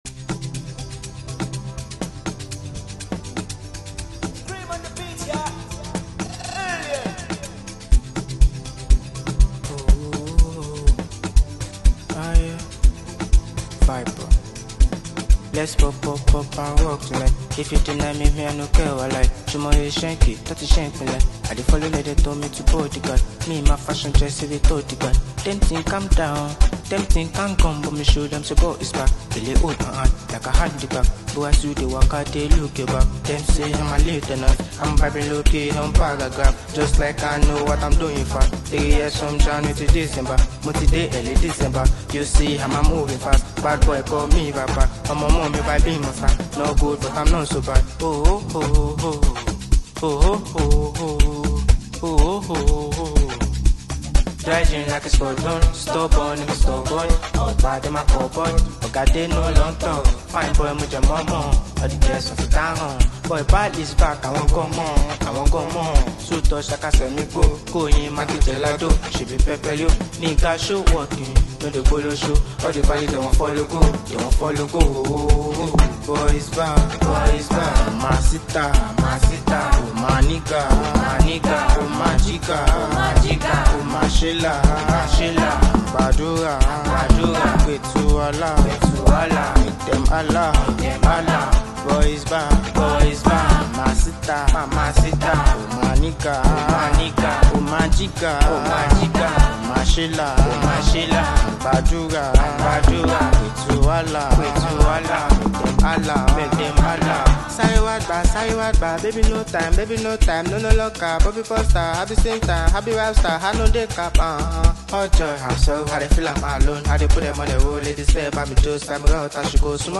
vibrant and confident